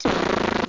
Amiga 8-bit Sampled Voice
uzi.mp3